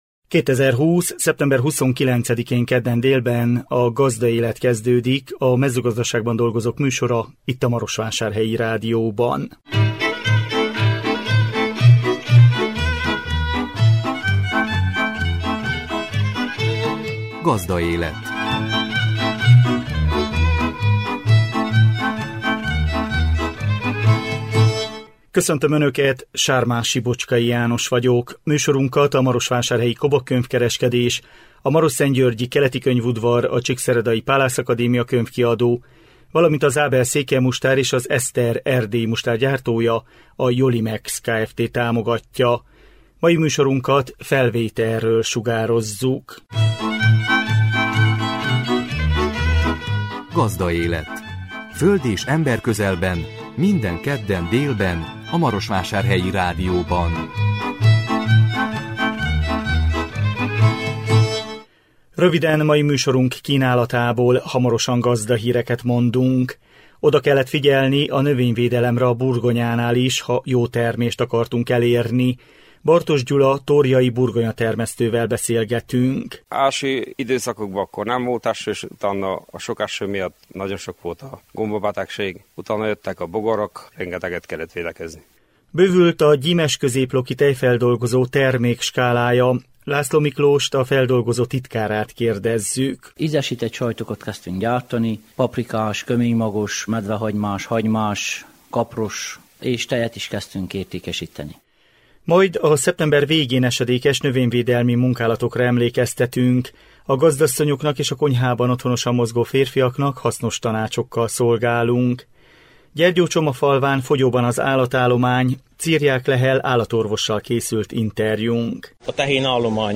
Gazdahírek